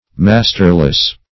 Masterless \Mas"ter*less\, a.